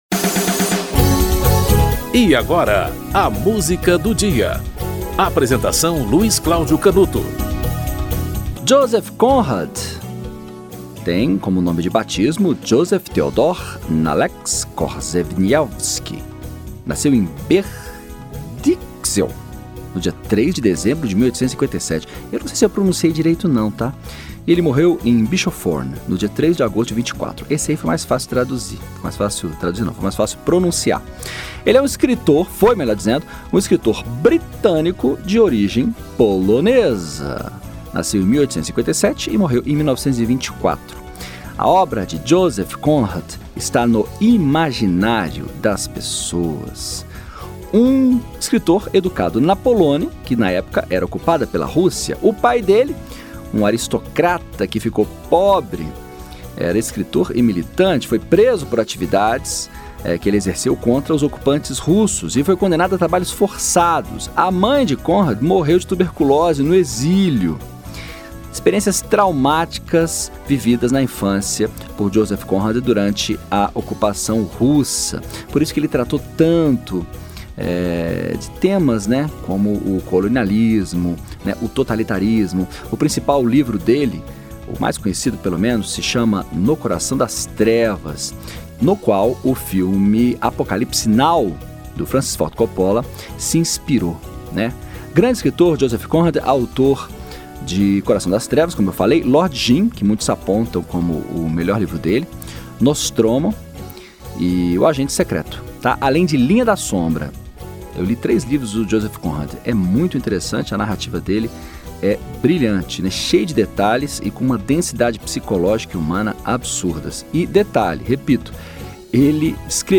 Filarmônica de Berlim - A Cavalgada das Valquírias (Richard Wagner)